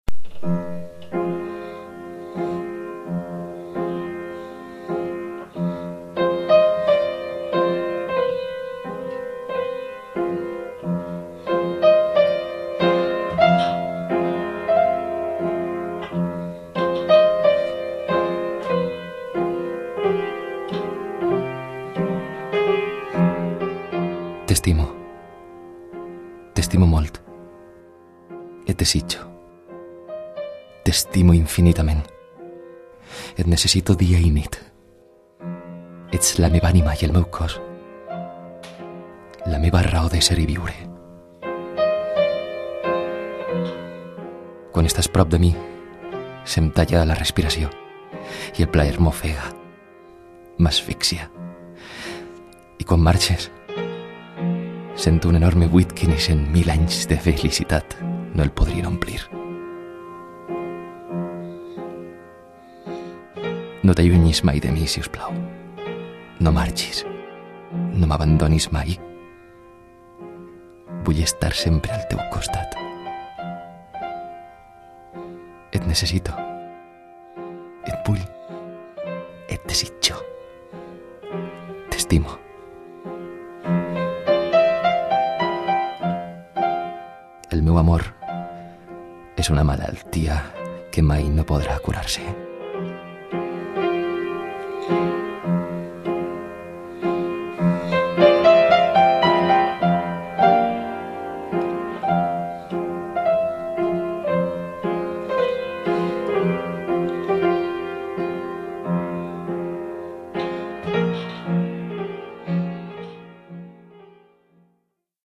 Al podcast d’asteroides d’avui he agafat prestada la veu, a més a més de la música.